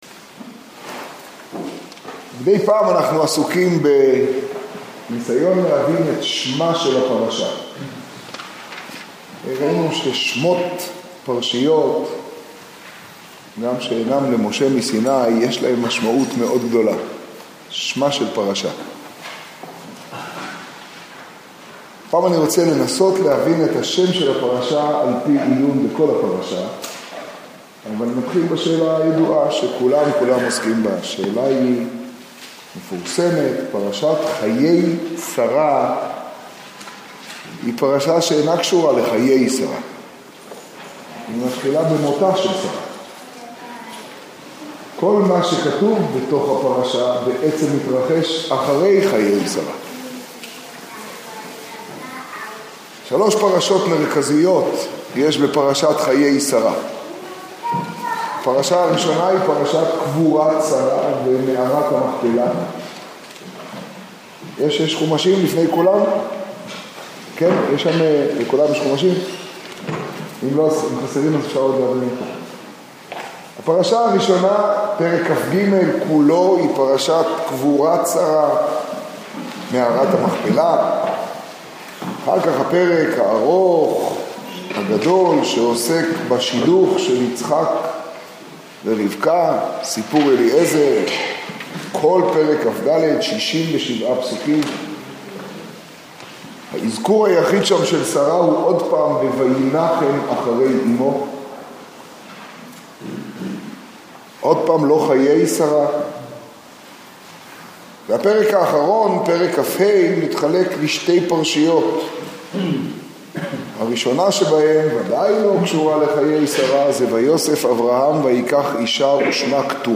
השיעור בירושלים, פרשת חיי שרה תשעז.